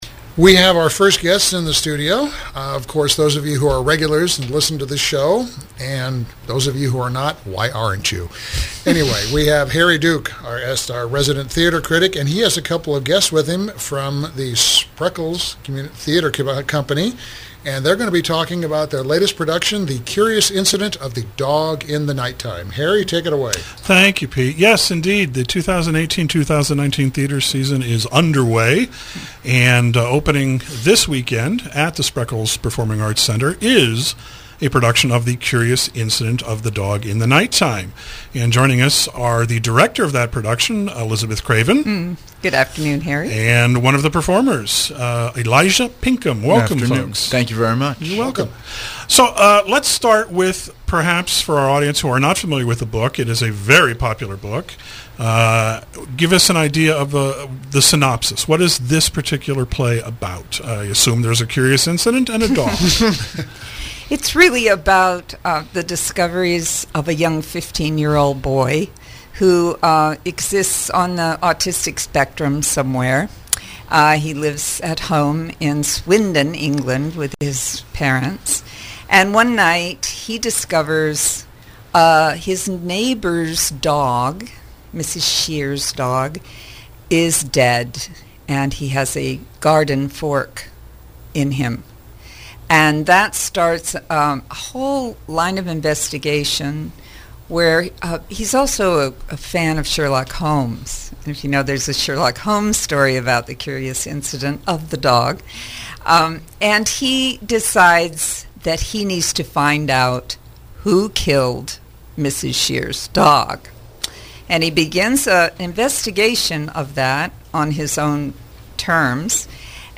KSRO Interview